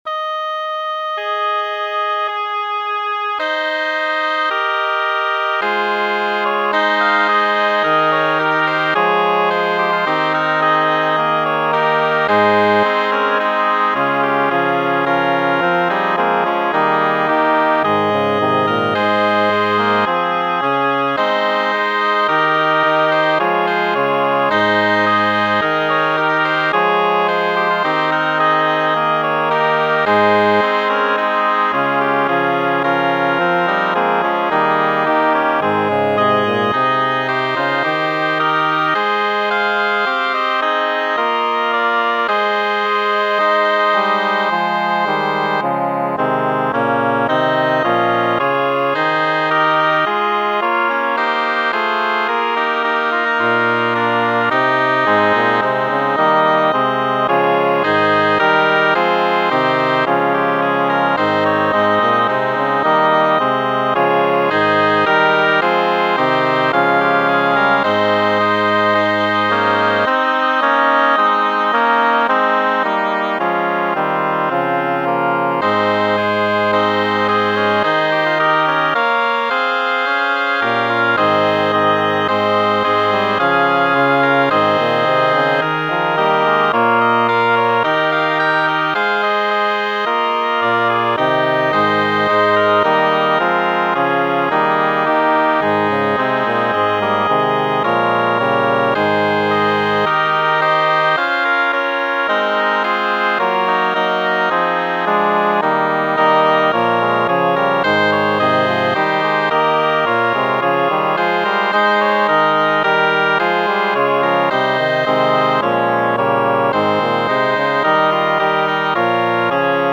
Number of voices: 6vv Voicings: SSATBB or SSTTBB Genre: Sacred, Motet, Gradual for the Twenty-second Sunday after Pentecost
Language: Latin Instruments: A cappella
A joyful setting of Psalm 133 (132 Vulgate)